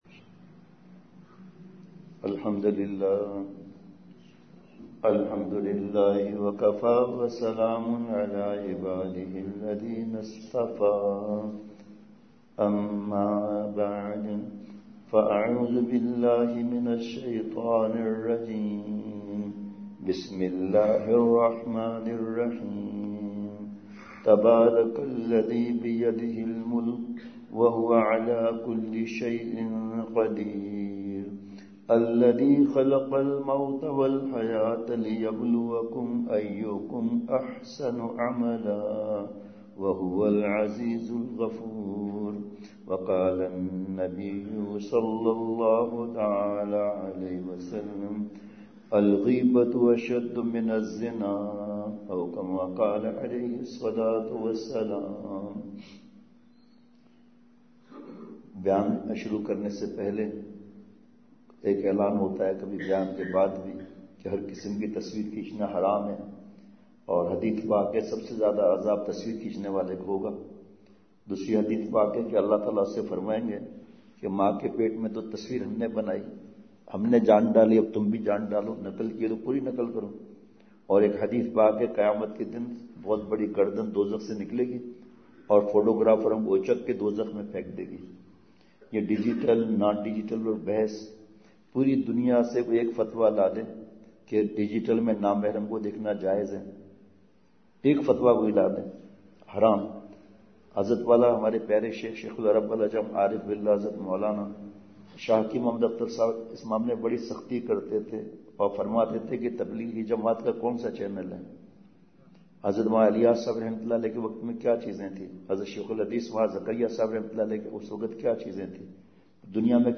فجر نماز چھ بجے ادا کرنے کے بعد بیان آغاز ہوا۔۔